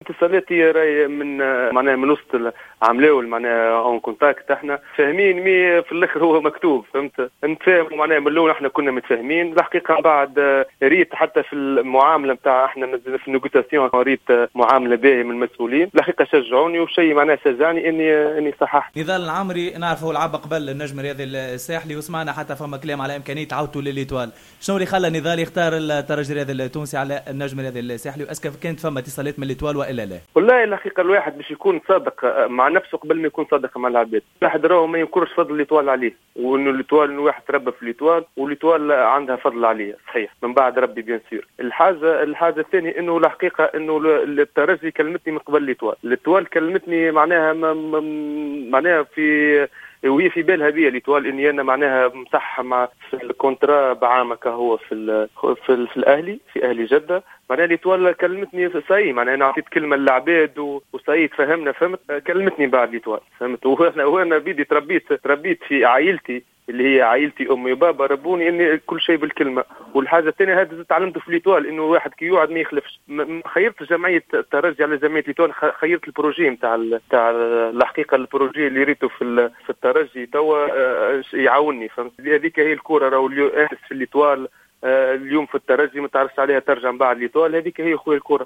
في تصريح لجوهرة أف أم